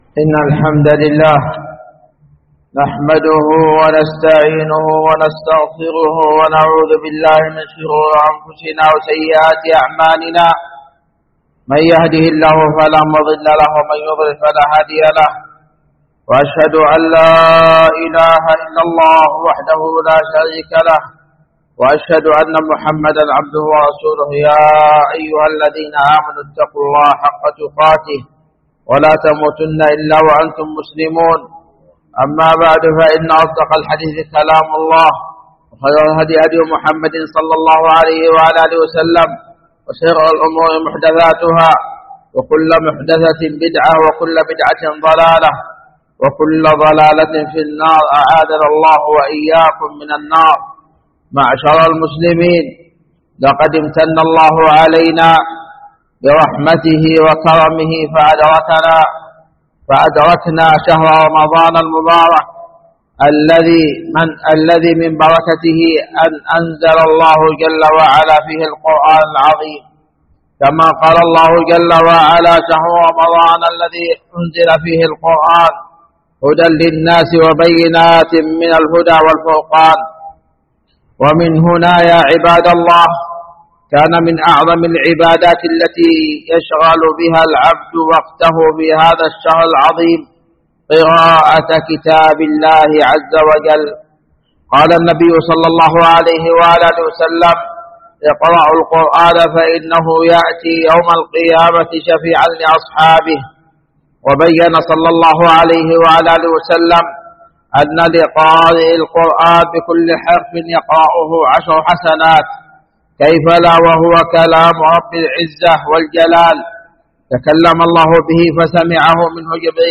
سماع الخطبة